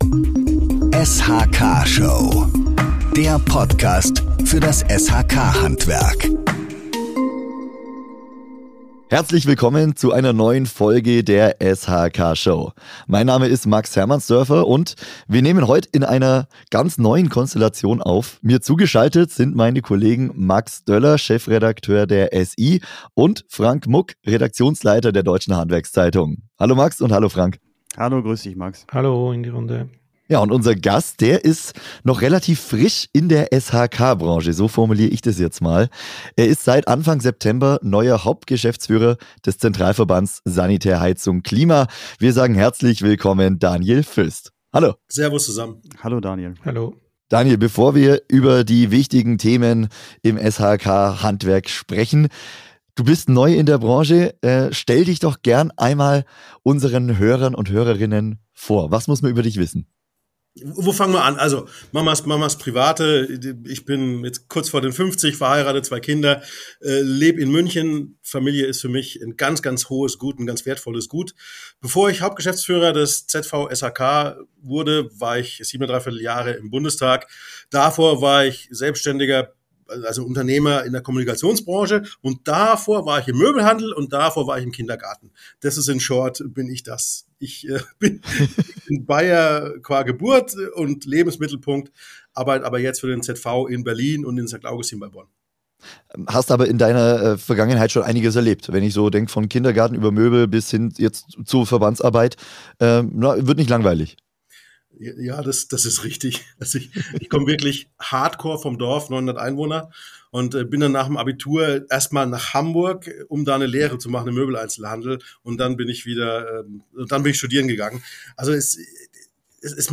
Der ehemalige FDP-Bundestagsabgeordnete erzählt, warum er nach Jahren in der Politik ins Handwerk gewechselt ist – und was ihn an seiner neuen Aufgabe reizt. Gemeinsam sprechen sie über die großen Baustellen der Branche: zu viel Bürokratie, zu wenig Fachkräfte und jede Menge politische Regelungen, die oft an der Praxis vorbeigehen.